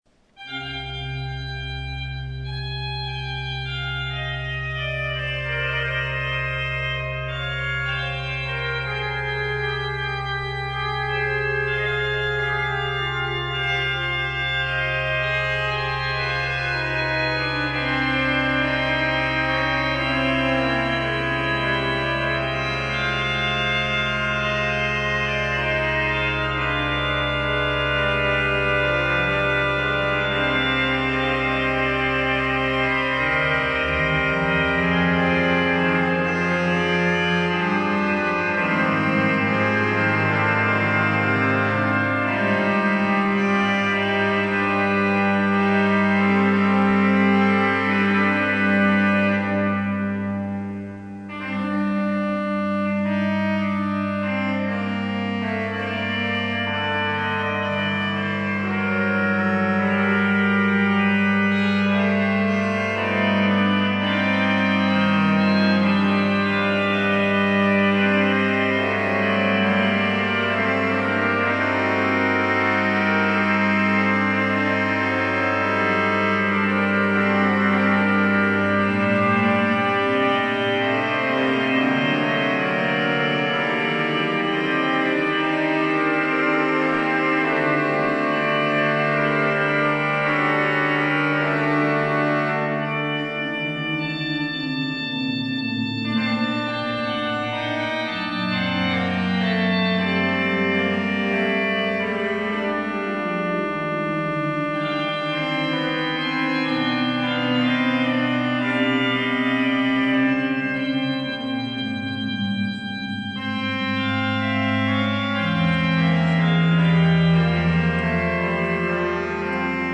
de l'église prieurale Saint-Pierre et Saint-Paul
Les extraits montrent quelques échantillons des sonorités particulières de l'orgue.